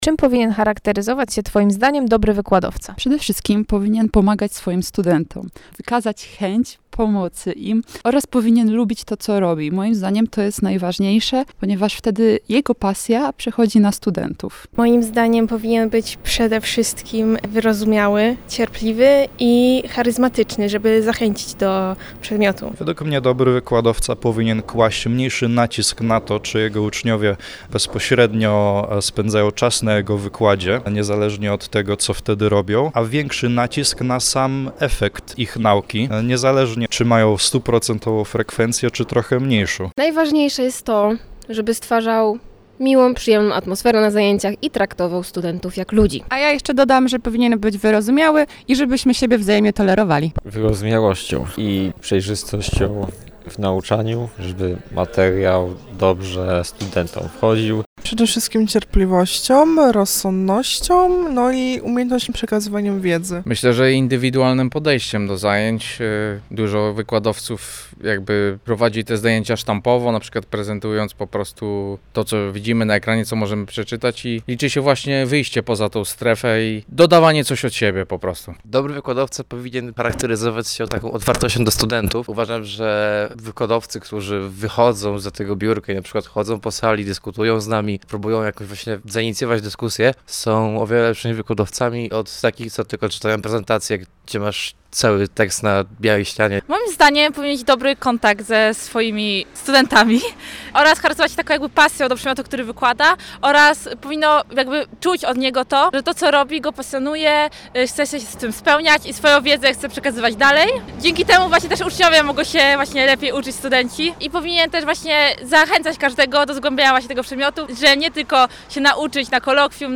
zapytać studentów, czym powinien charakteryzować się dobry wykładowca.